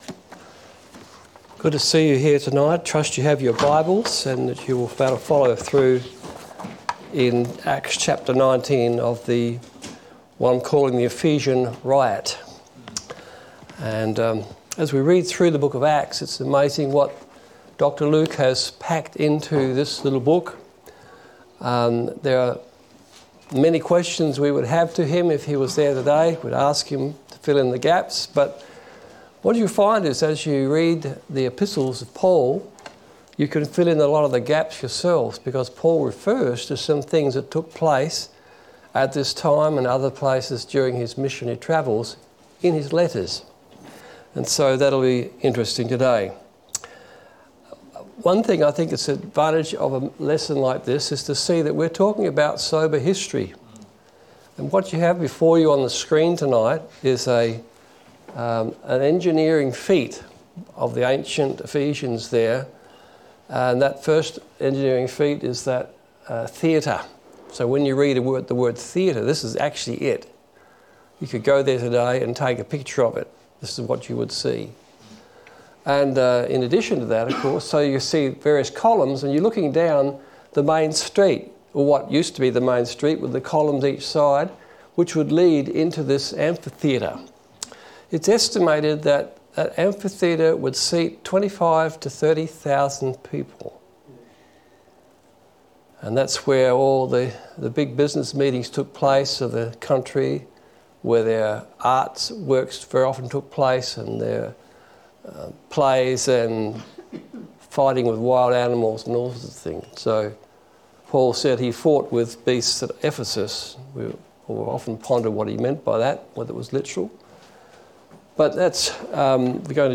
Series: Acts Of The Apostles Service Type: Sunday Evening